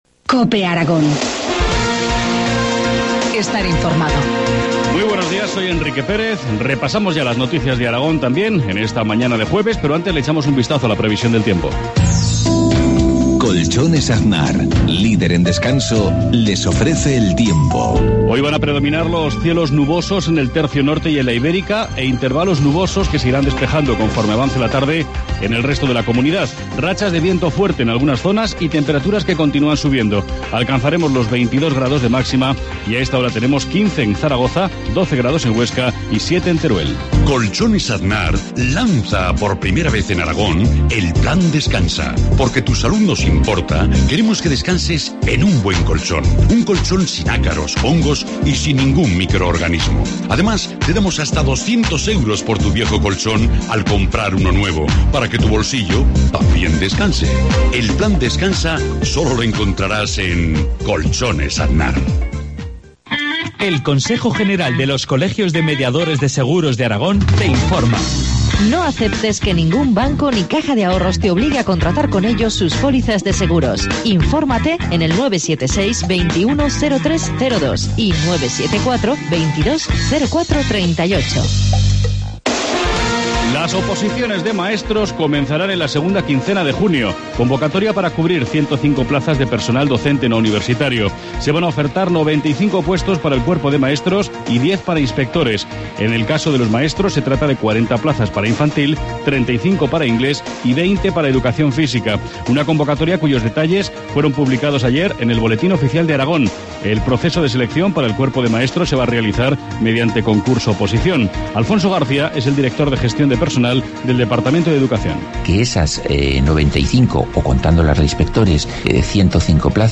Informativo matinal, jueves 11 de abril, 7.53 horas